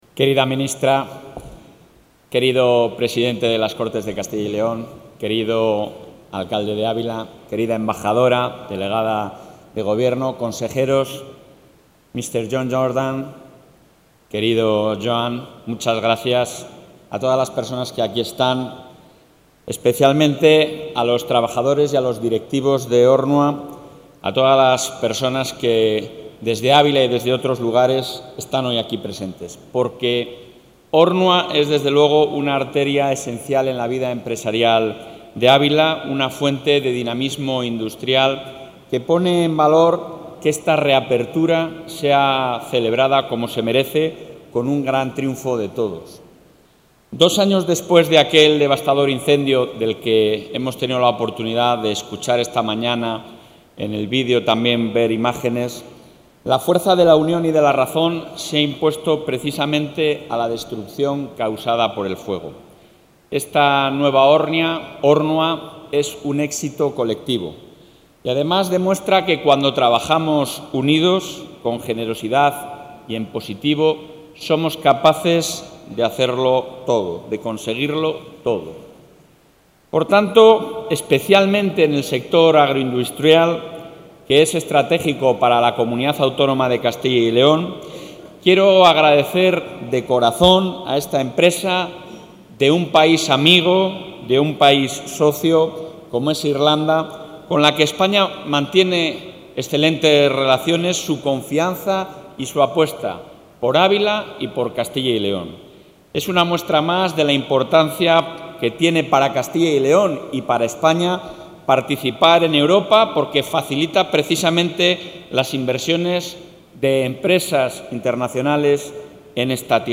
Durante su intervención en el acto organizado en las nuevas instalaciones de la fábrica de Ornua, en Ávila, el presidente del...
Audio presidente.